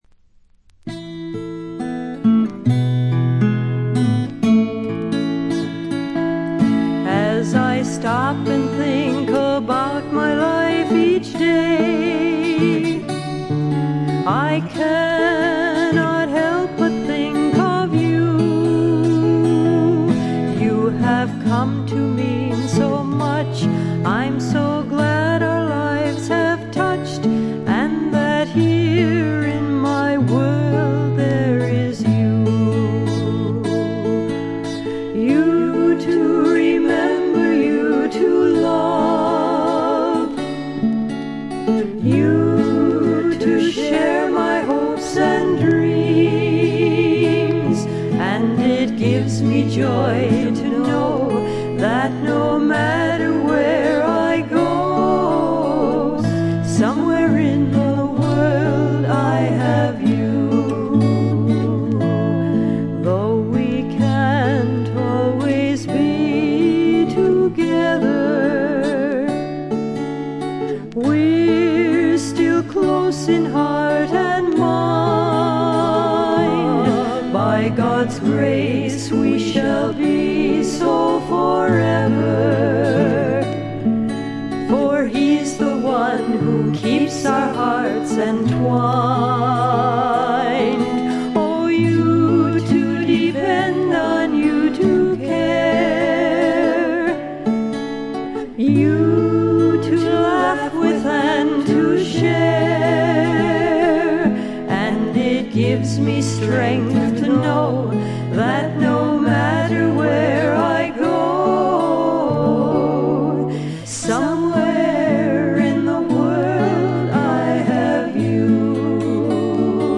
軽いチリプチ程度。
ミネアポリス産クリスチャン・ミュージック／女性シンガーソングライターの佳作。
この時点で一児の母親のようですが、純真無垢な歌声に癒やされます。
試聴曲は現品からの取り込み音源です。
Recorded At - Sound 80 Studios